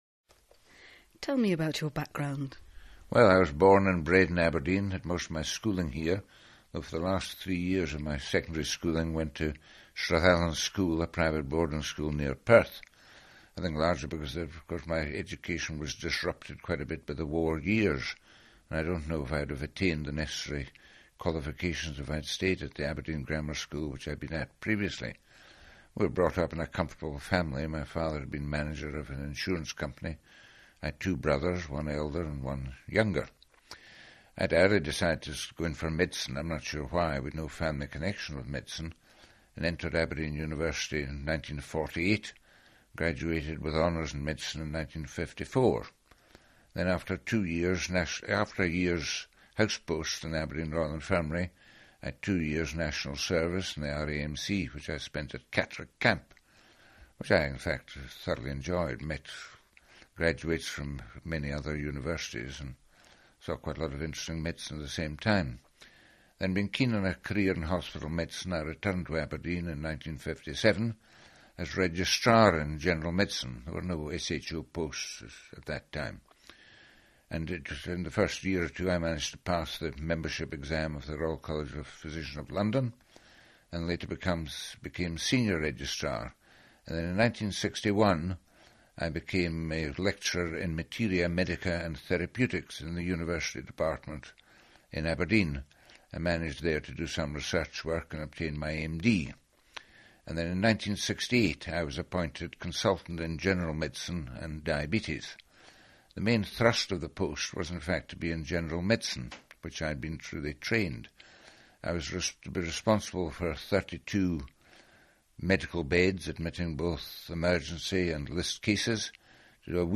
Available interview tracks